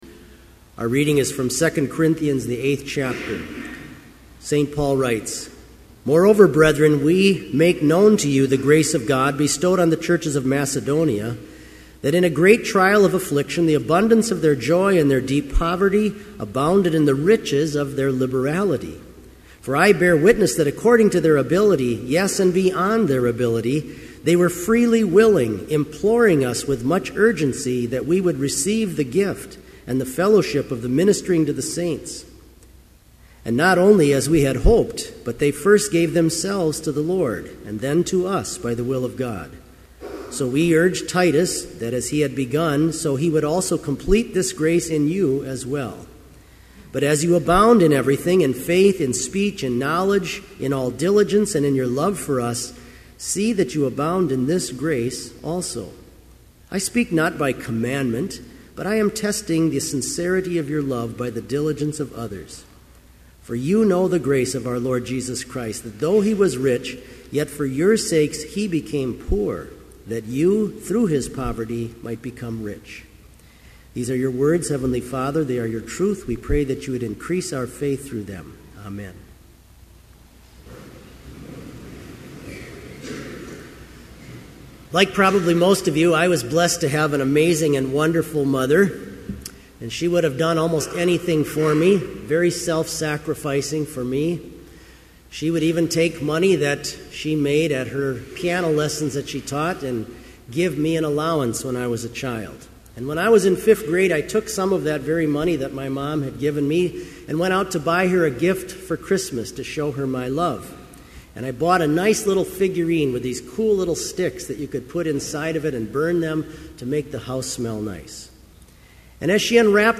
Complete Service
• Homily
• Postlude - Chapel Brass
This Chapel Service was held in Trinity Chapel at Bethany Lutheran College on Friday, September 23, 2011, at 10 a.m. Page and hymn numbers are from the Evangelical Lutheran Hymnary.